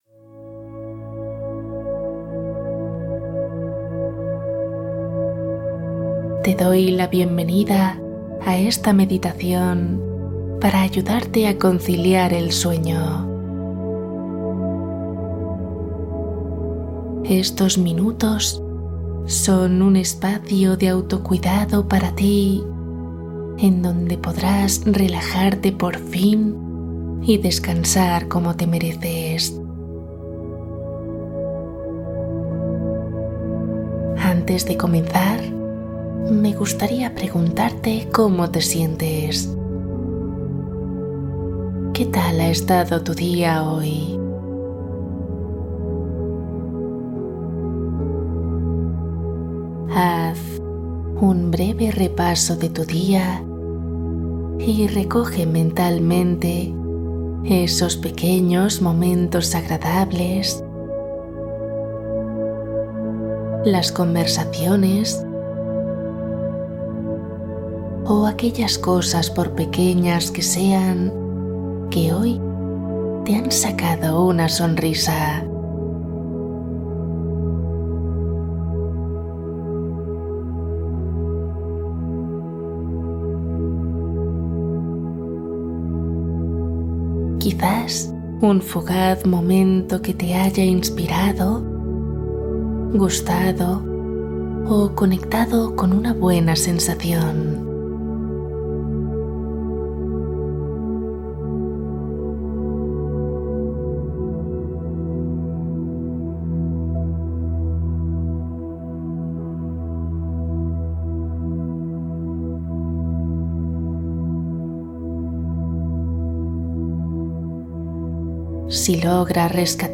Relato relajante para soltar la respiración